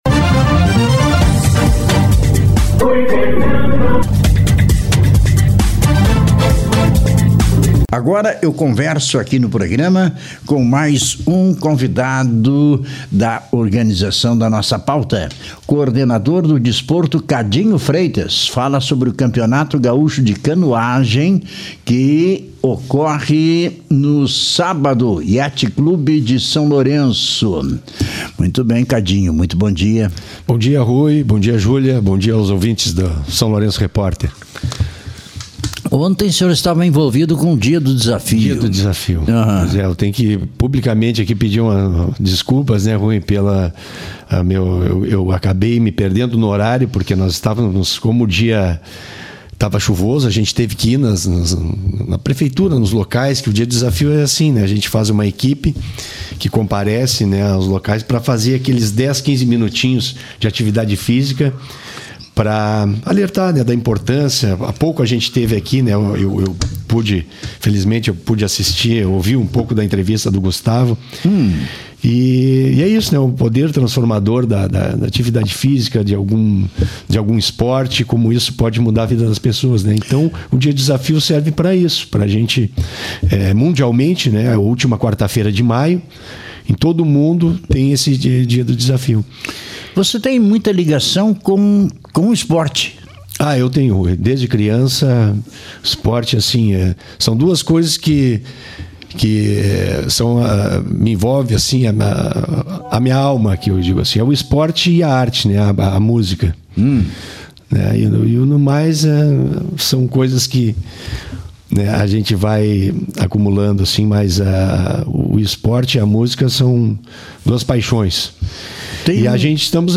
O Atleta Olímpico, hoje Vereador Givago Ribeiro, em Santa Maria, participou da entrevista e falou da expectativa sobre o evento aqui no município.